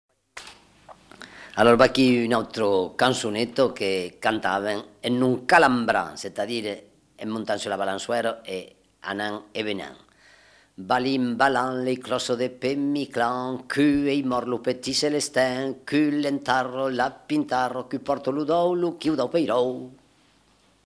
Aire culturelle : Haut-Agenais
Lieu : Lauzun
Genre : forme brève
Type de voix : voix d'homme
Production du son : récité
Classification : formulette enfantine